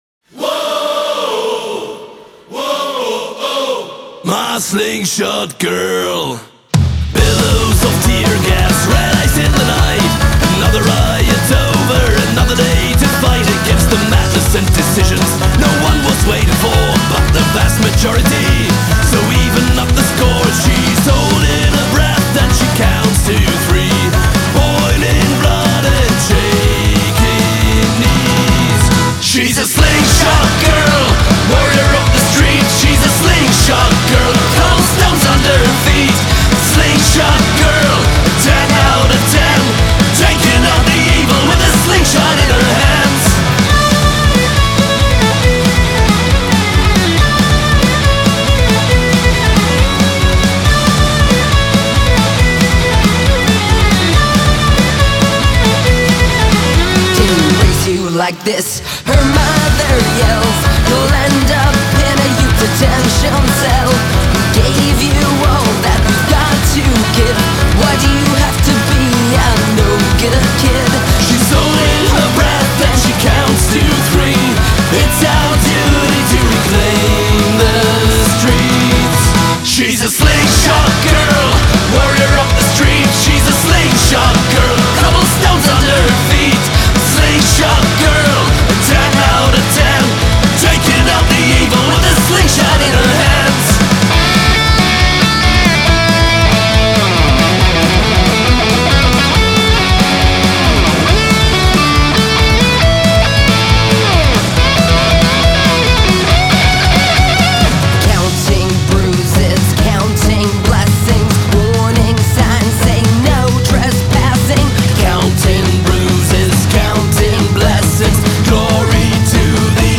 Genre: Rock-Folk